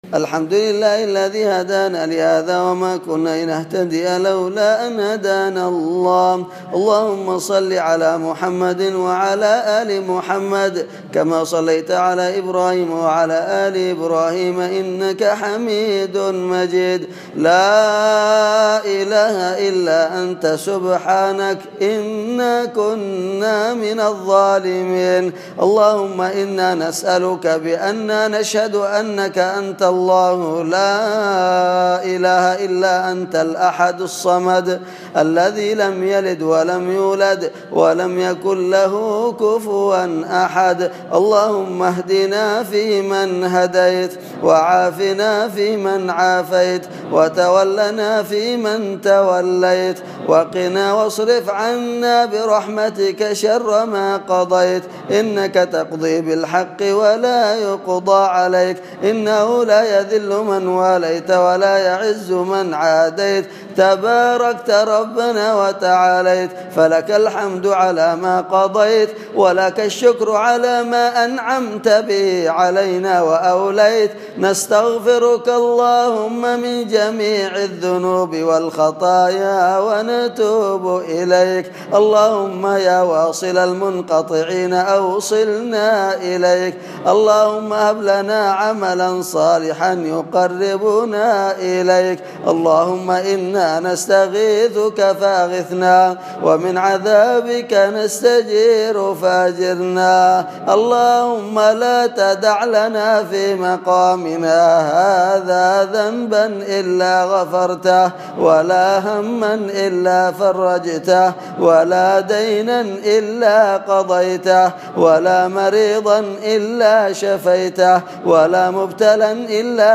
أدعية وأذكار
تسجيل لدعاء القنوت المؤثر